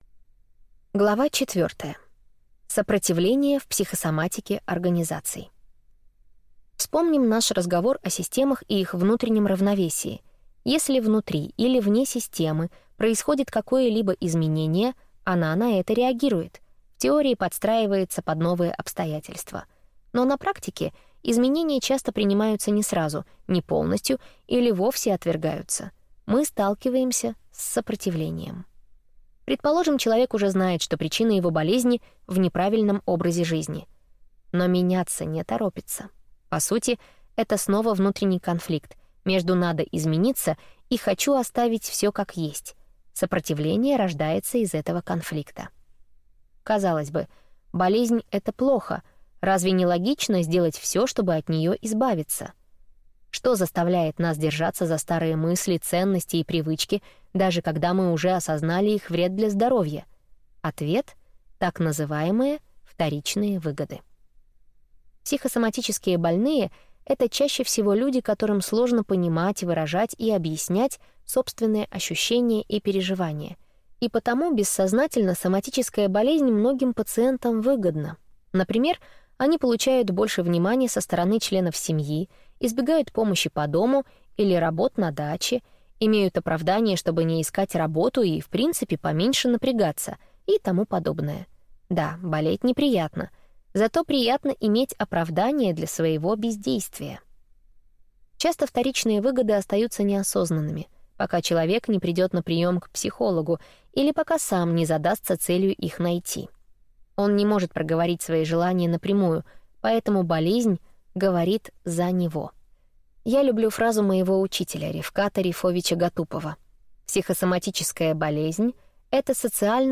Аудиокнига Психосоматика компаний. Как выявить реальные причины проблем в бизнесе и лечить не симптомы, а болезнь | Библиотека аудиокниг